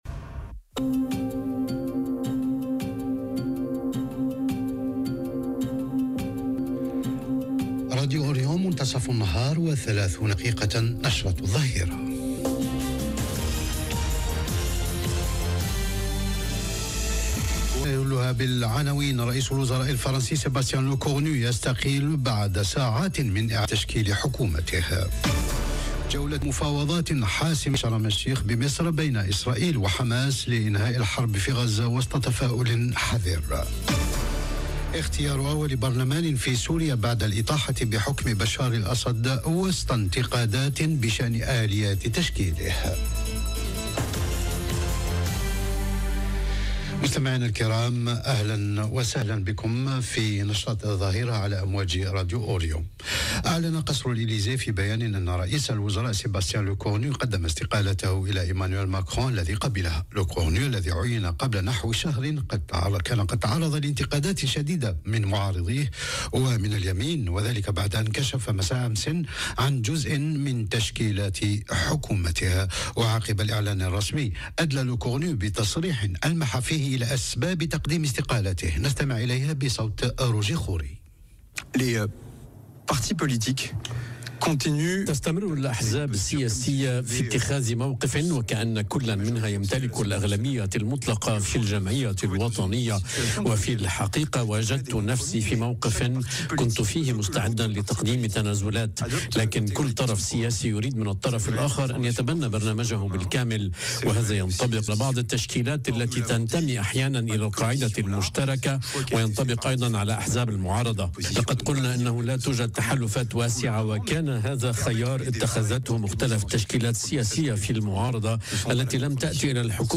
نشرة أخبار الظهيرة: رئيس الوزراء الفرنسي سيباستيان لوكورنو يستقيل بعد ساعات من إعلان تشكيلة حكومته - Radio ORIENT، إذاعة الشرق من باريس